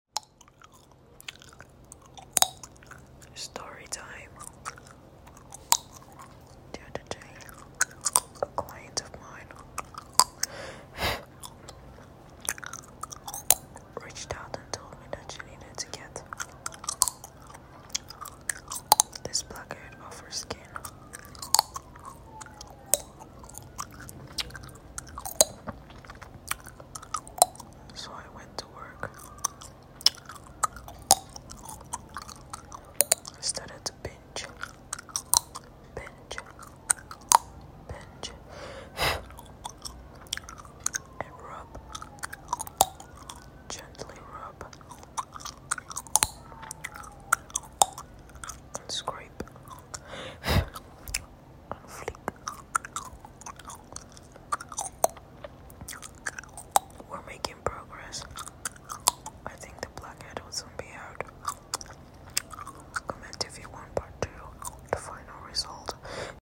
Skin massage and blackhead cleaning